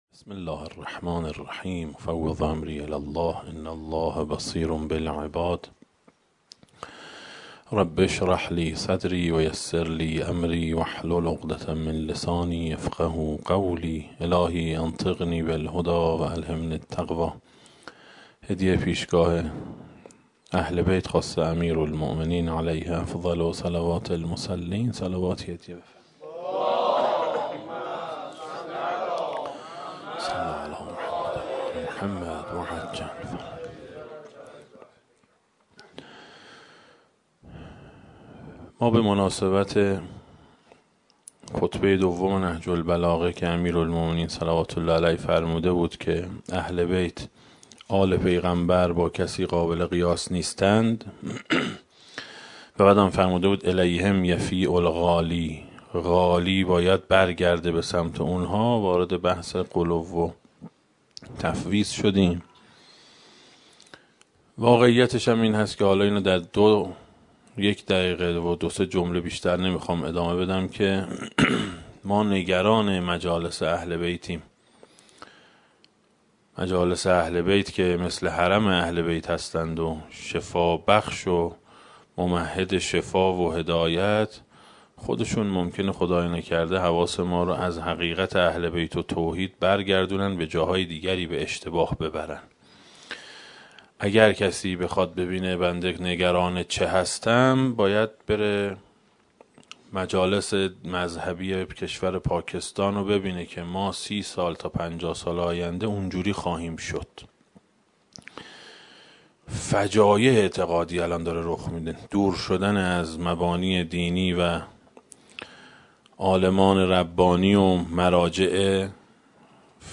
دسته: امیرالمومنین علیه السلام, درآمدی بر نهج البلاغه, سخنرانی ها